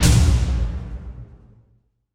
Big Drum Hit 26.wav